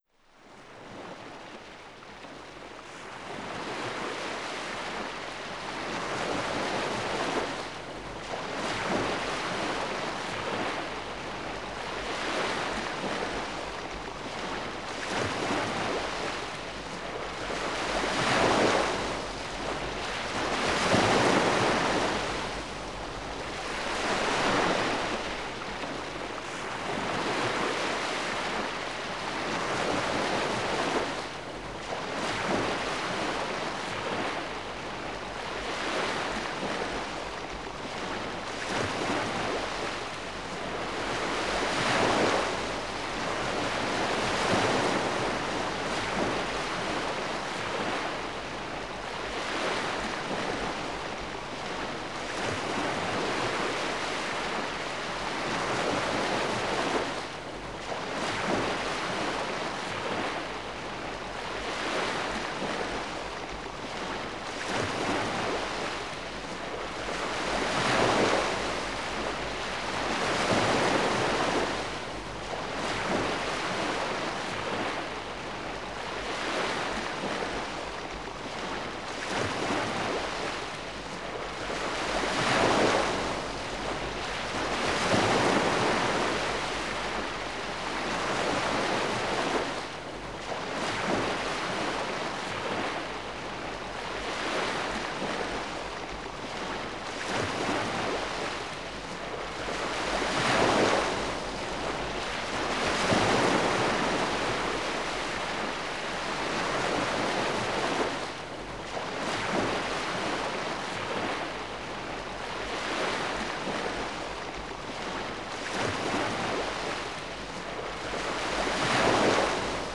seawaves_outdoors